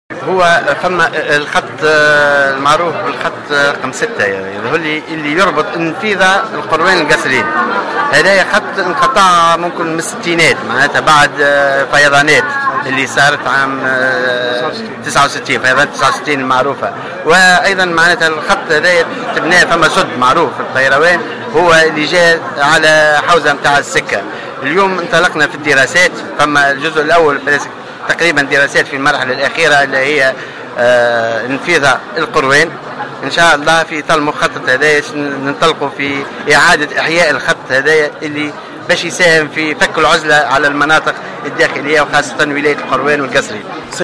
وقال في تصريحات اليوم السبت على هامش إشرافه في المهدية على حفل تكريم المتقاعدين وأبناء العاملين بشركة النقل بالساحل، إن هذا الخط انقطع منذ نهاية الستينات على اثر فياضانات وتم التخطيط لاعادة فتحه.